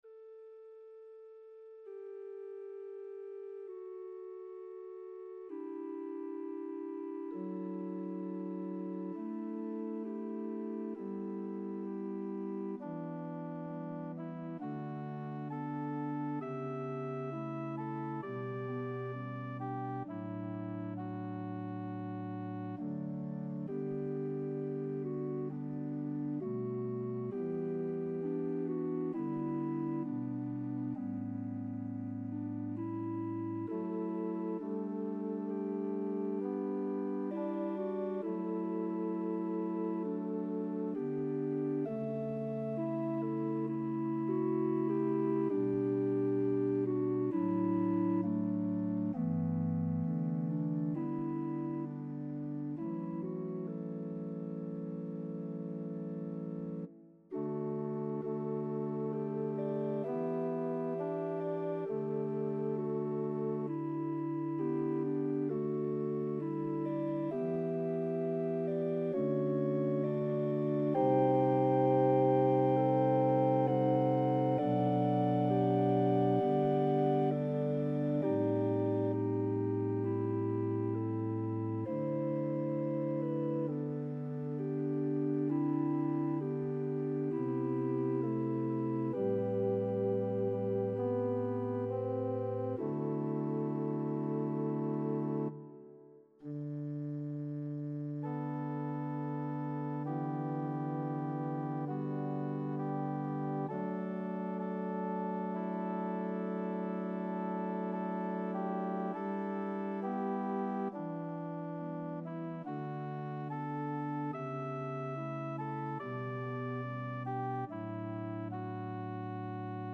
Organ Solo